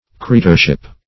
Creatorship \Cre*a"tor*ship\, n. State or condition of a creator.